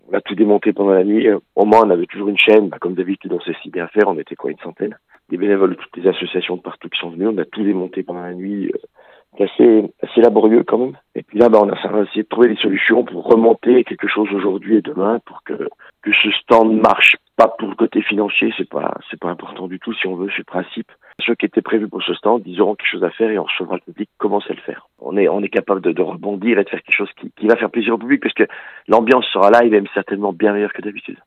Vincent Humbert, le Président de ces Grandes Médiévales (et maire d'Andilly) revient sur cette volonté de maintenir la fête grâce à la solidarité de tous.